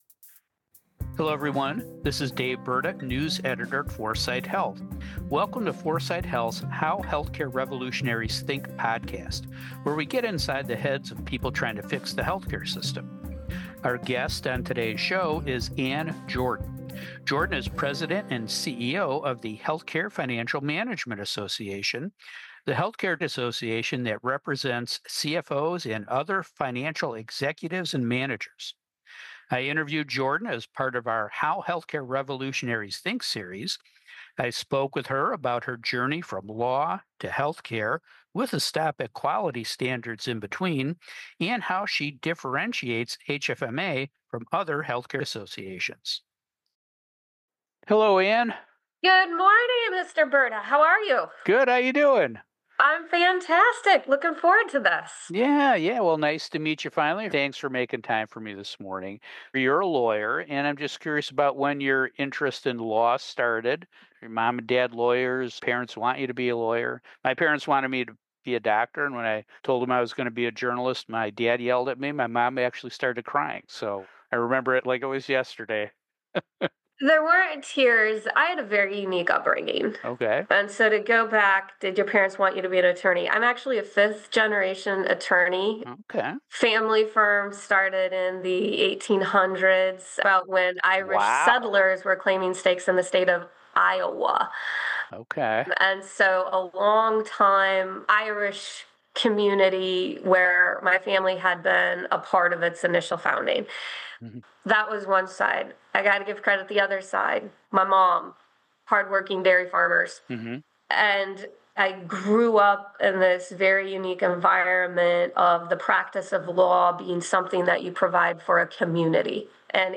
podcast interview for 4sight Health’s How Healthcare Revolutionaries Think series.